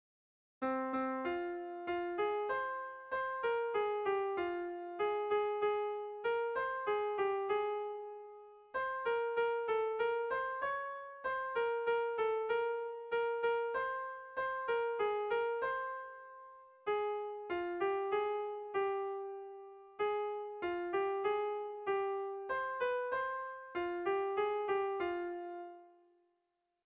Erlijiozkoa
Seiko handia (hg) / Hiru puntuko handia (ip)
ABB